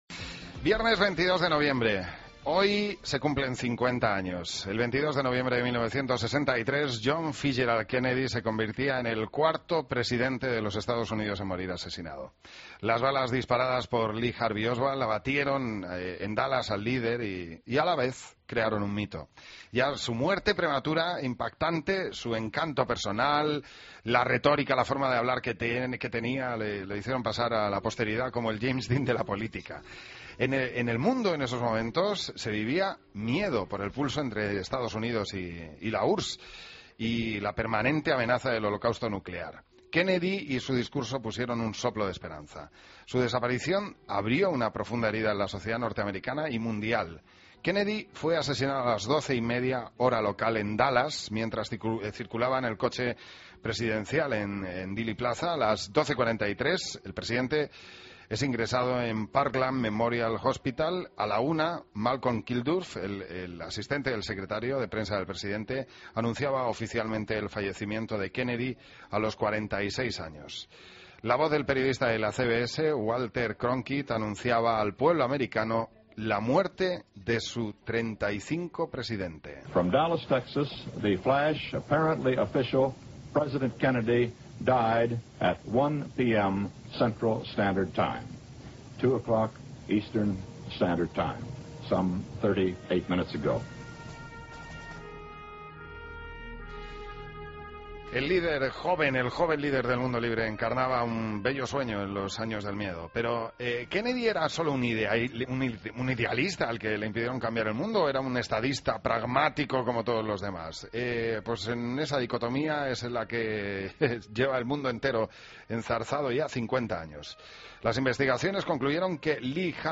La Mañana 50 años después, Dallas rememora la muerte de JFK. Crónica